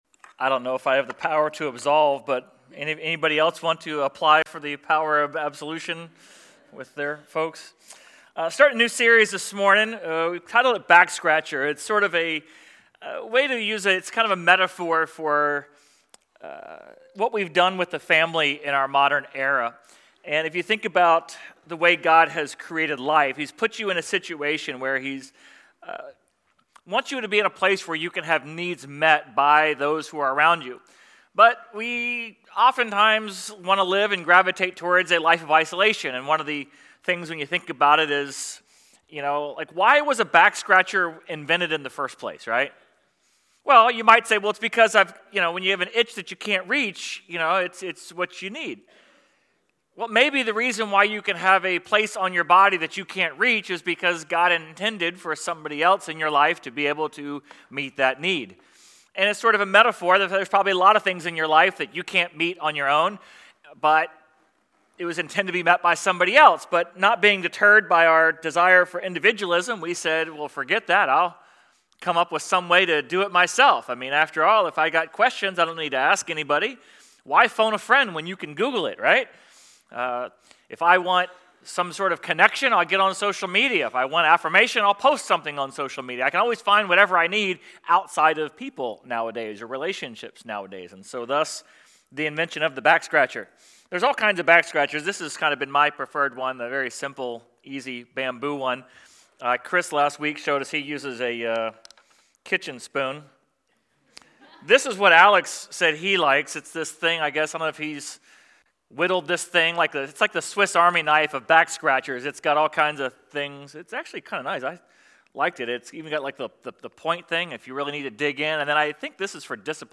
Sermon_4.12.26.mp3